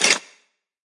来自我的卧室的声音 " 铅笔袋 ( 冻结 )
描述：在Ableton中录制并略微修改的声音